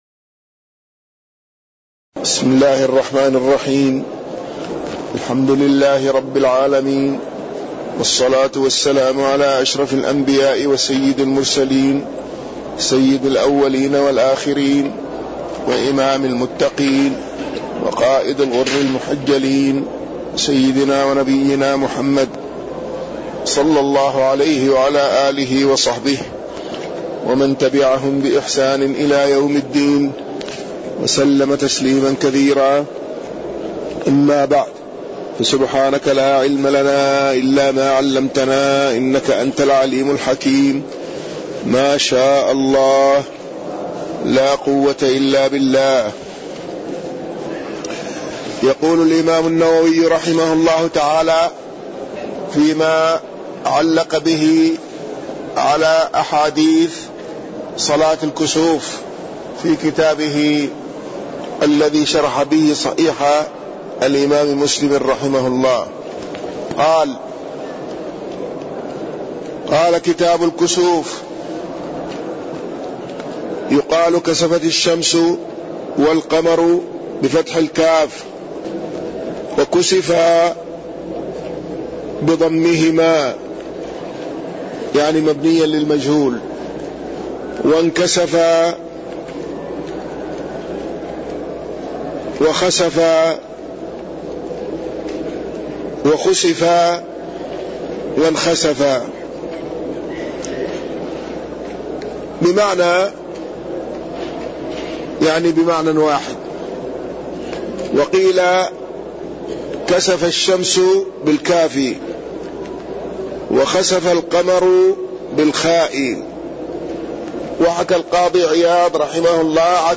تاريخ النشر ٢٢ محرم ١٤٣٢ هـ المكان: المسجد النبوي الشيخ